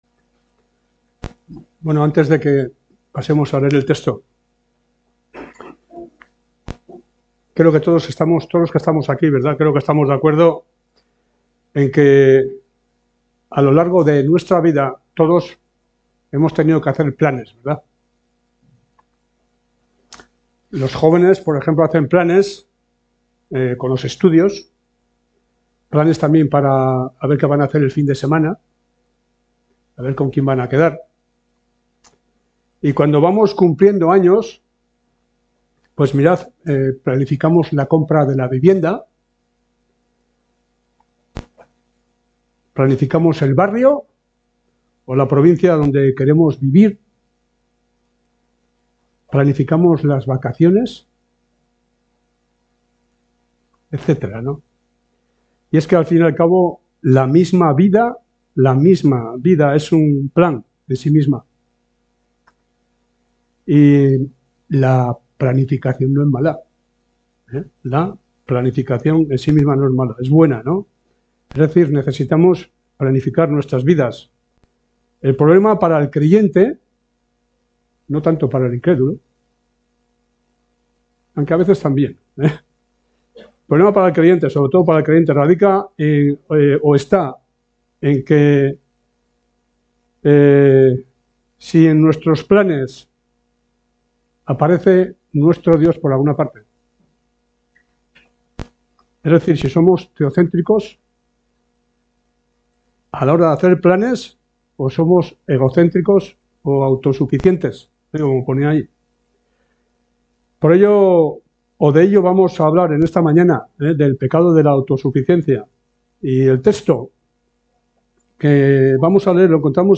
Predicación